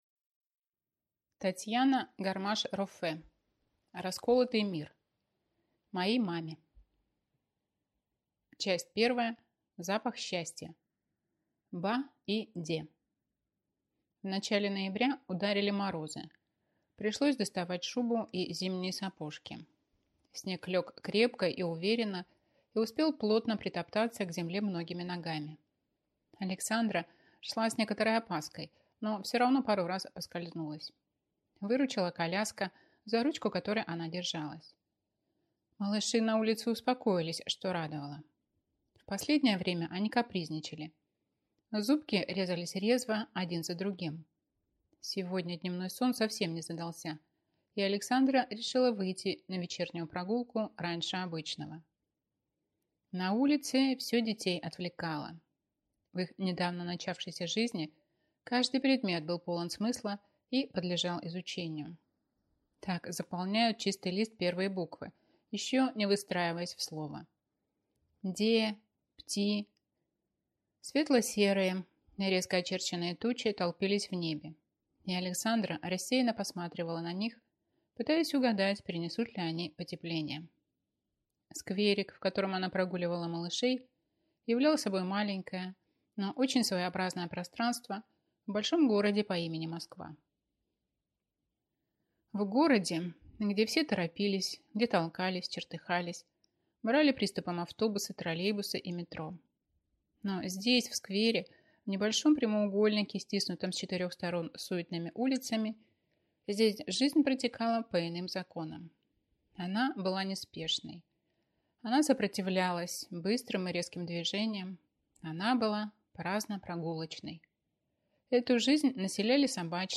Аудиокнига Расколотый мир - купить, скачать и слушать онлайн | КнигоПоиск